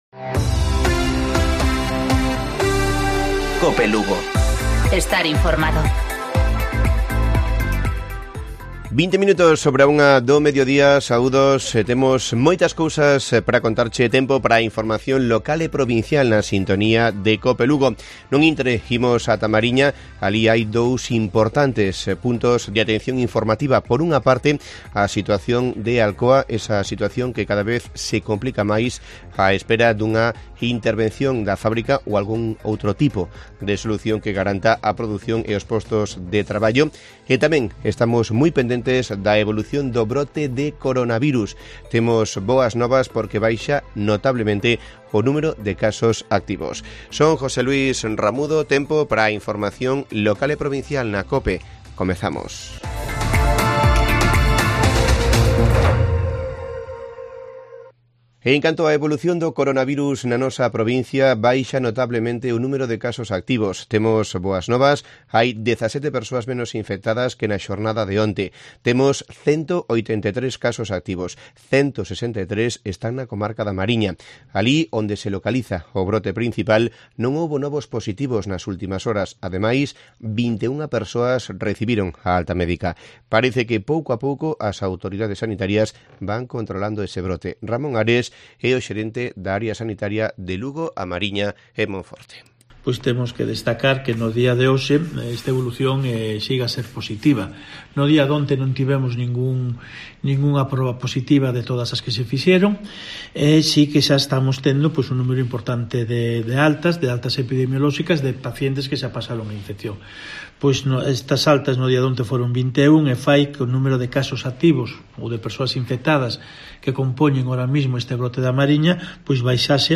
Informativo Provincial Cope Lugo. 14 de julio. 13:20-13:30 horas